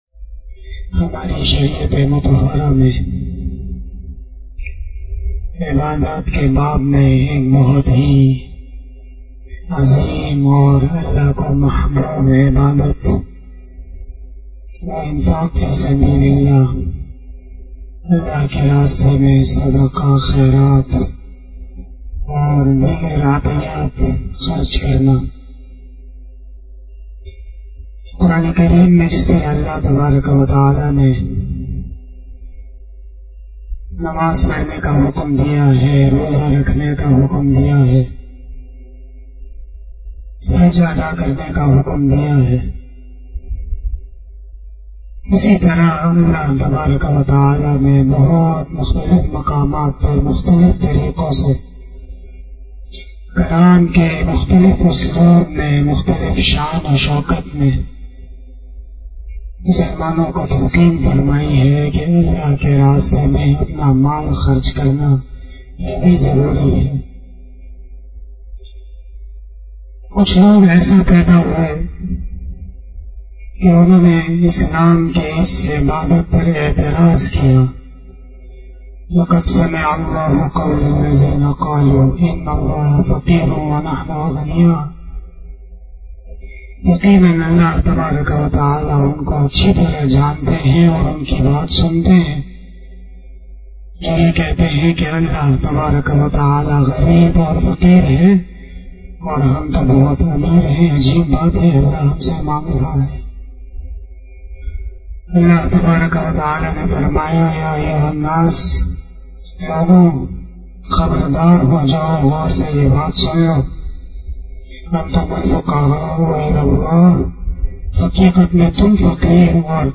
012 After Fajar Namaz Bayan 21 April 2021 ( 08 Ramadan 1442HJ) Wednesday